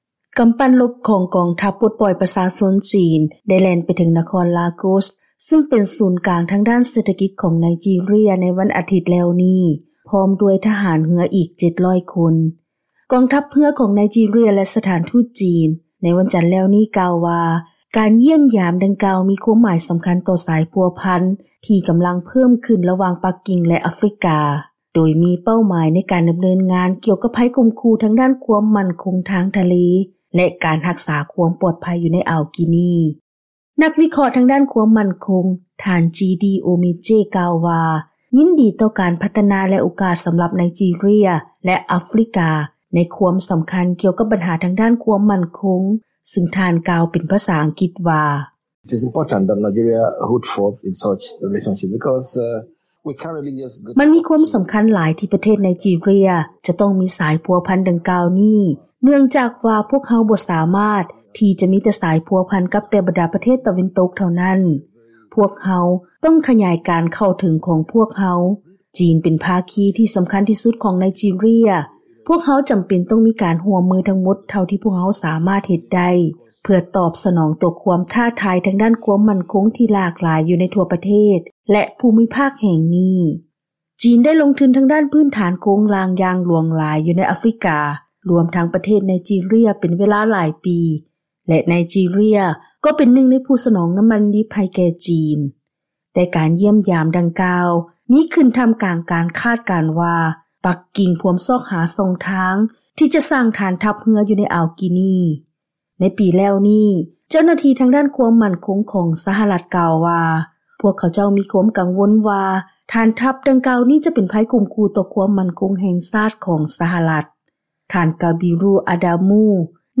ເຊີນຮັບຟັງລາຍງານກ່ຽວກັບ ການຊັ່ງຊາຂອງນັກຊ່ຽວຊານ ຕໍ່ການຢ້ຽມຢາມໄນຈີເຣຍ ຂອງກອງທັບເຮືອຈີນ